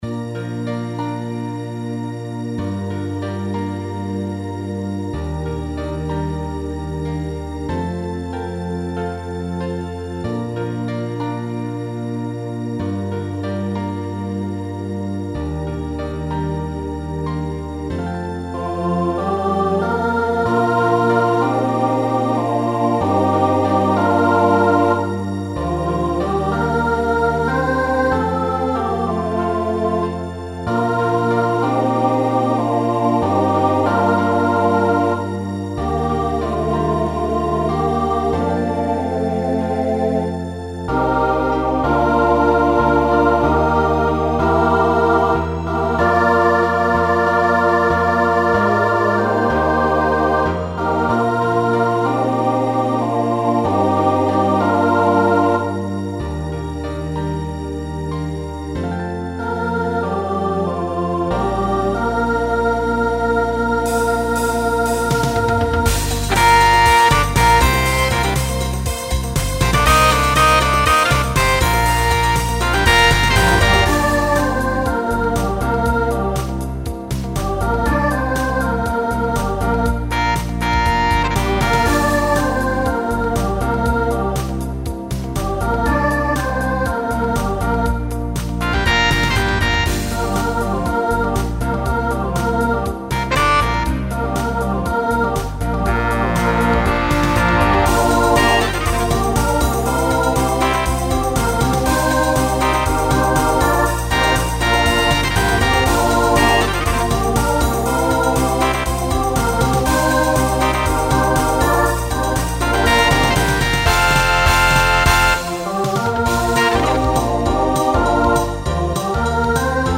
Voicing SAB